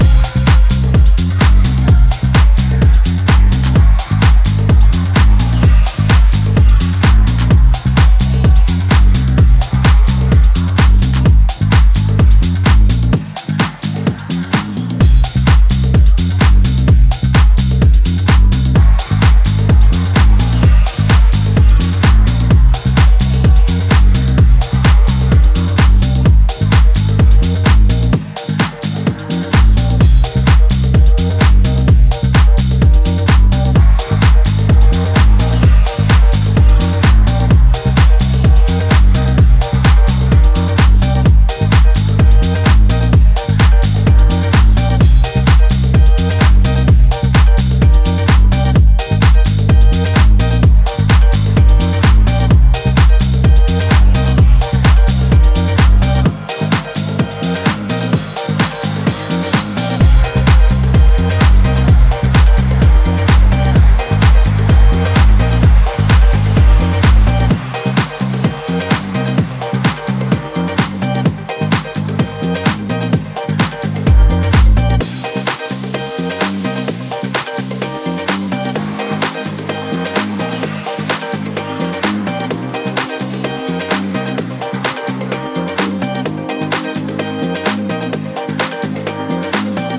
もう少しアップリフトに仕立てたそちらもやはりさじ加減が絶妙です！